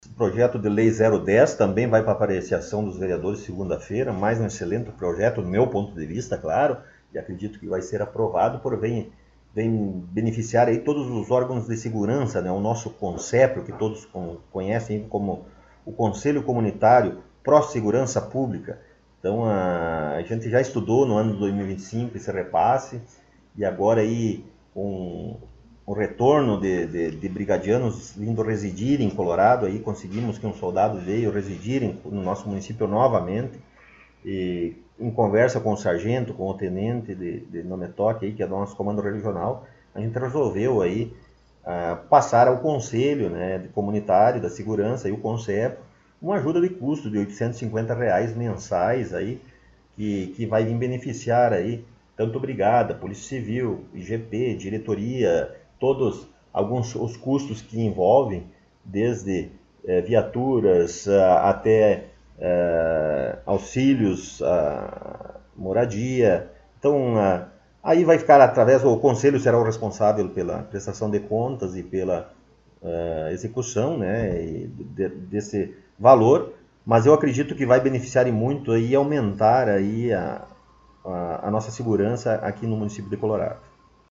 Prefeito Rodrigo Sartori concedeu entrevista
Na última semana, mais uma vez, o jornal Colorado em Foco teve a oportunidade de entrevistar o prefeito Rodrigo Sartori em seu gabinete na Prefeitura Municipal.